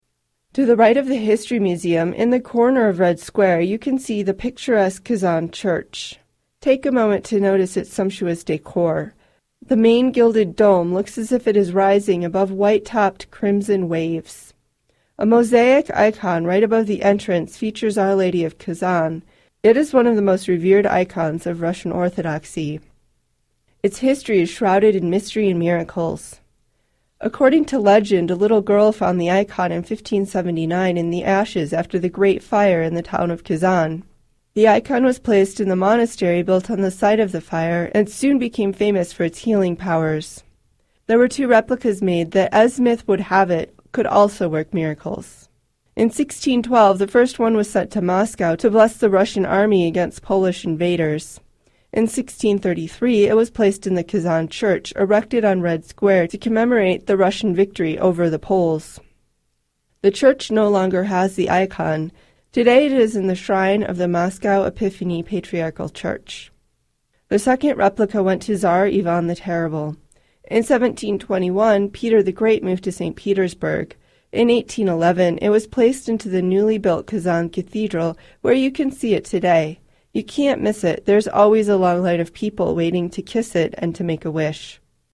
Type: iPod/MP3 Audio Tours
All information has been carefully researched for accuracy and is presented by professional narrators.